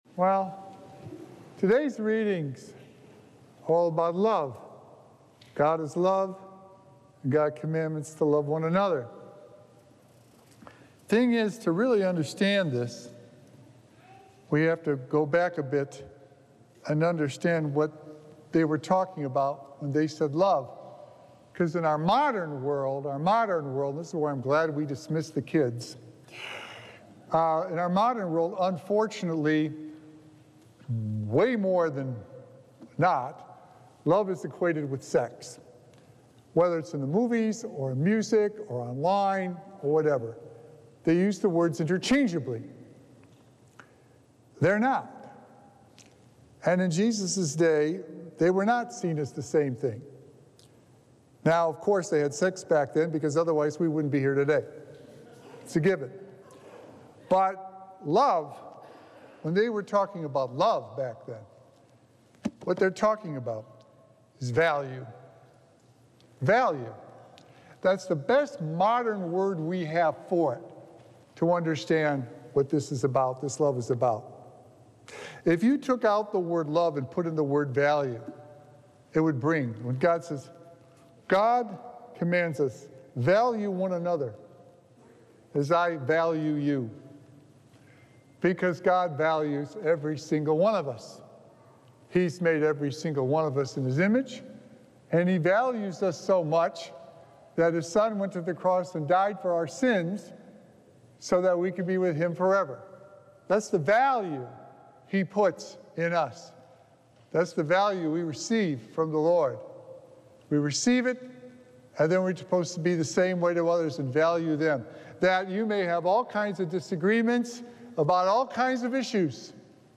Sacred Echoes - Weekly Homilies Revealed